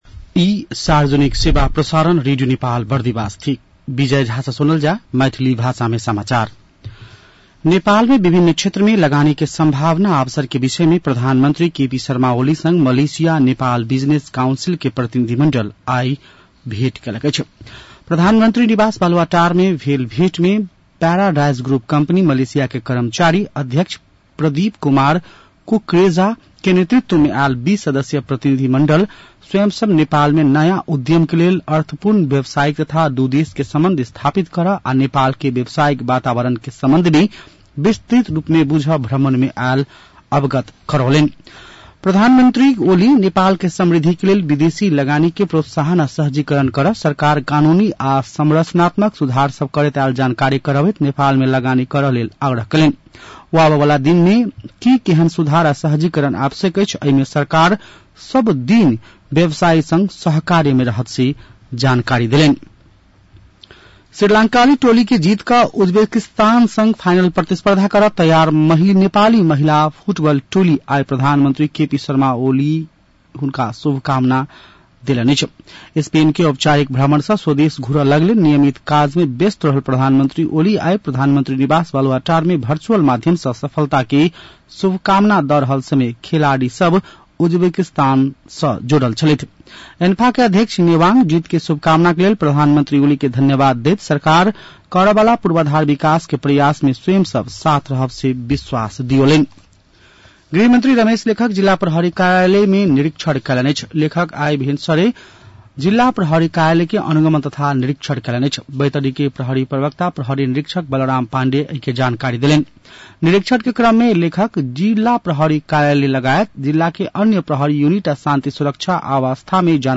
मैथिली भाषामा समाचार : २१ असार , २०८२
6-pm-Maithali-news-.mp3